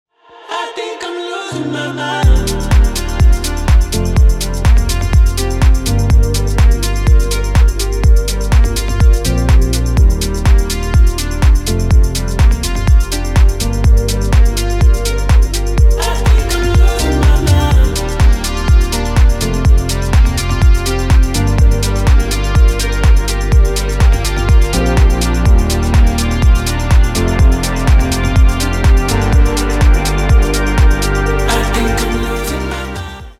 Прекрасный атмосферный рингтон в стиле melodic house